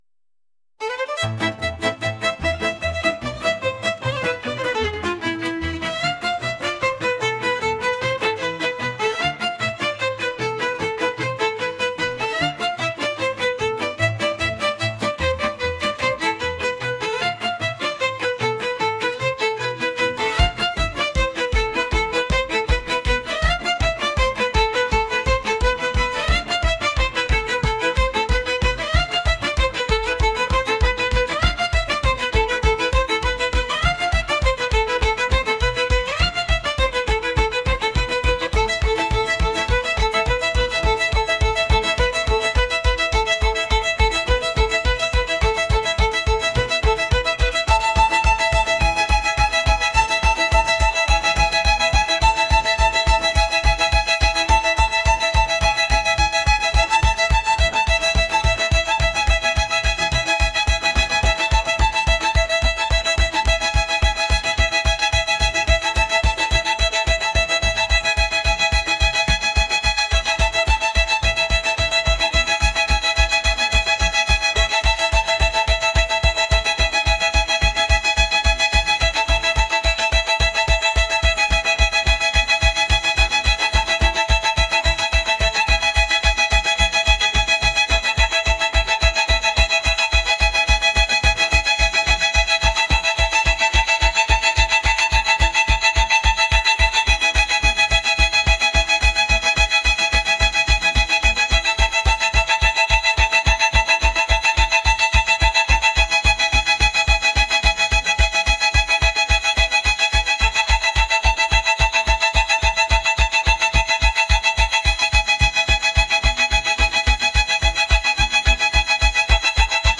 world | energetic